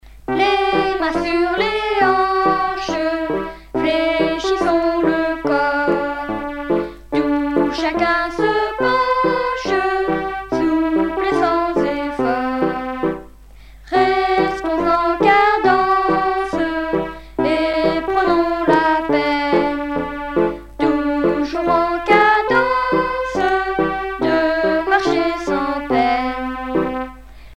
circonstance : éducation scolaire
Pièce musicale éditée